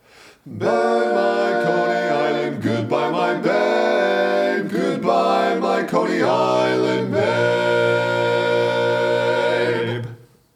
Key written in: B♭ Major
Type: Barbershop